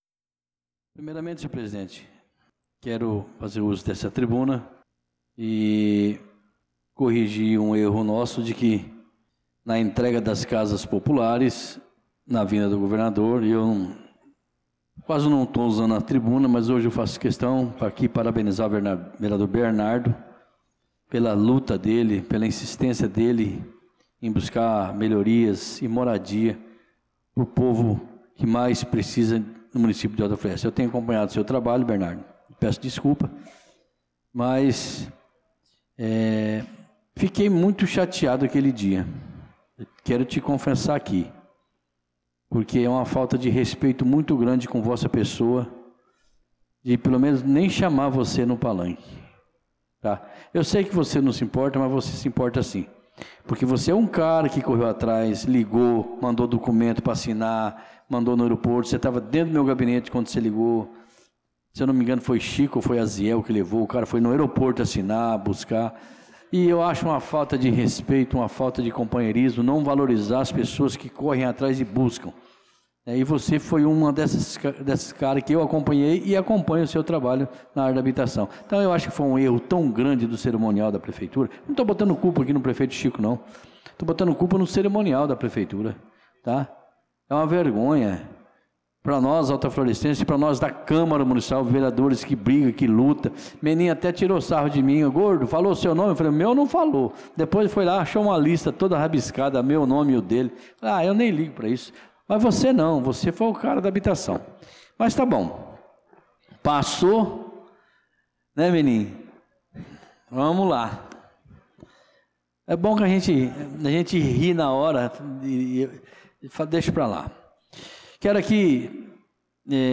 Pronunciamento do vereador Tuti na Sessão Ordinária do dia 16/06/2025.